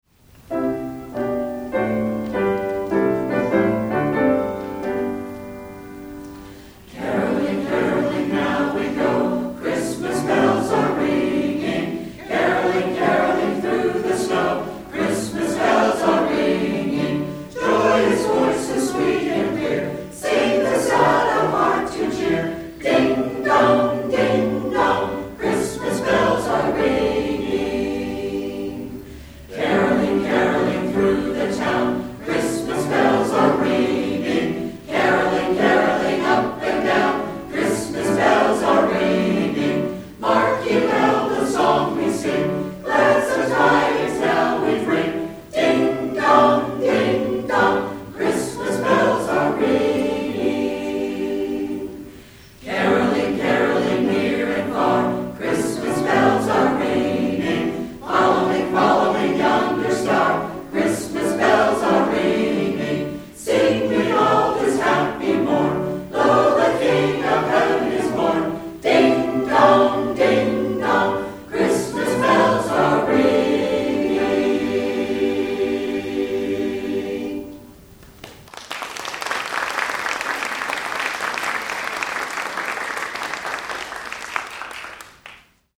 Center for Spiritual Living, Fremont, CA
2009 Winter Concert, Wednesday, December 16, 2009